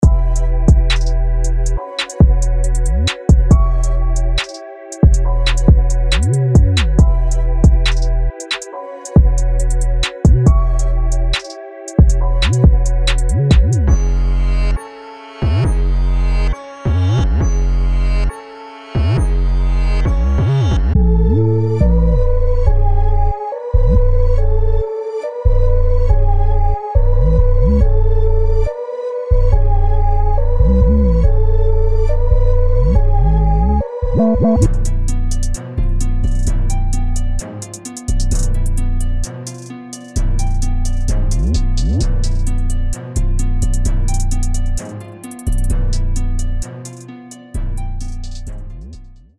• Mini Construction Kit
• Includes Drums